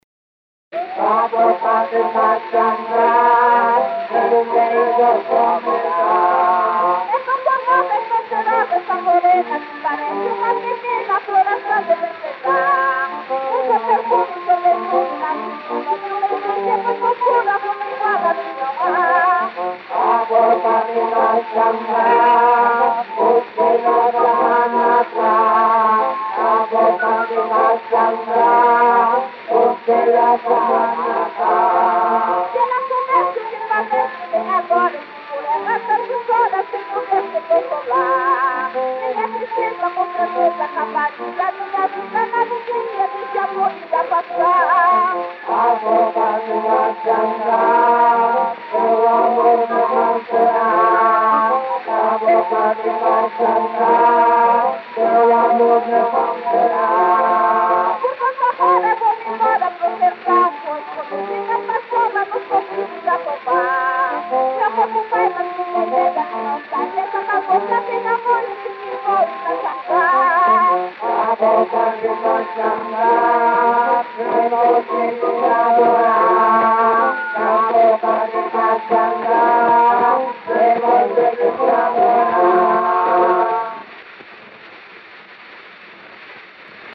Disco de 78 rotações, também chamado "78 rpm", gravado em apenas um lado e com rótulo "tricolor".
O gênero musical foi descrito como "Cançoneta".